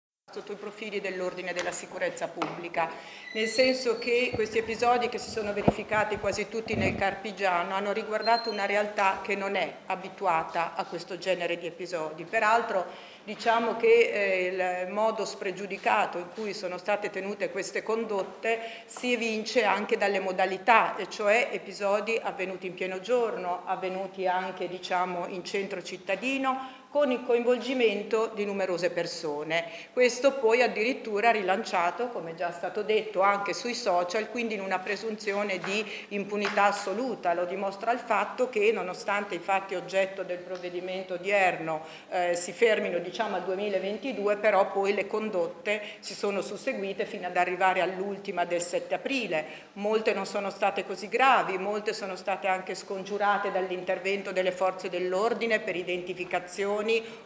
Nel file l’intervista al Questore di Modena Donatella Dosi